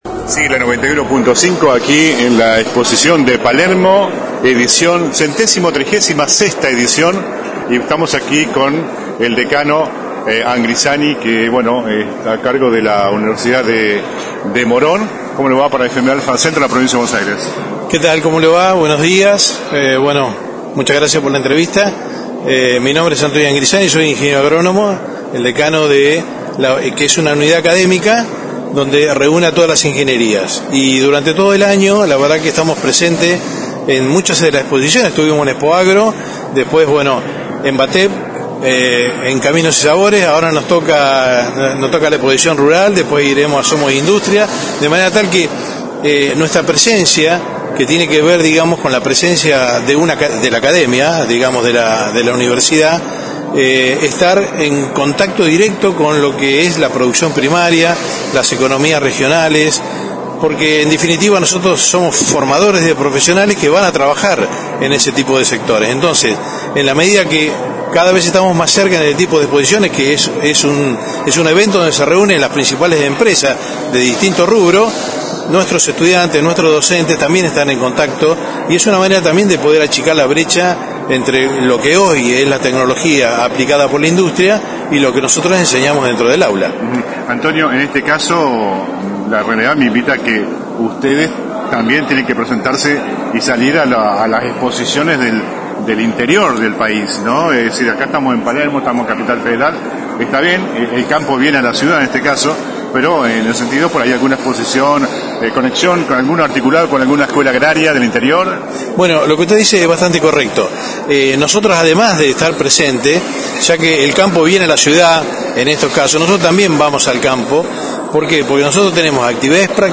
(galería de imágenes) La 91.5 dice presente una vez más en la mayor muestra del campo del país.